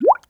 SINGLE BUB0A.wav